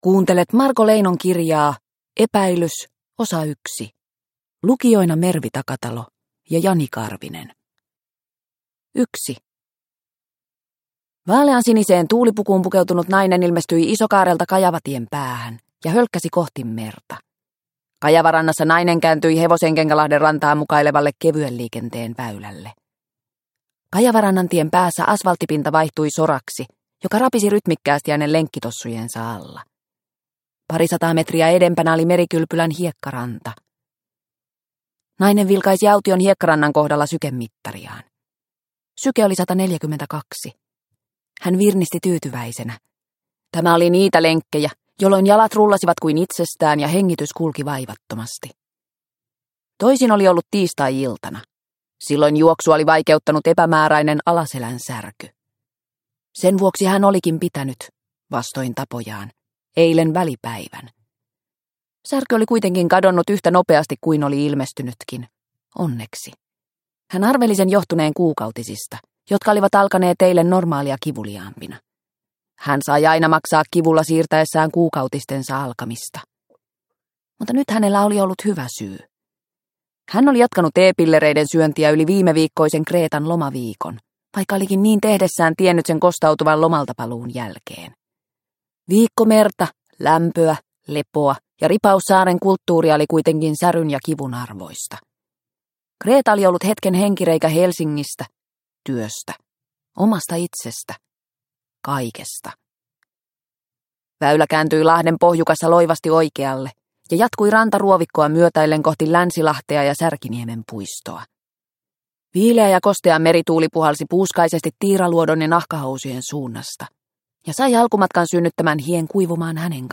Epäilys – Ljudbok – Laddas ner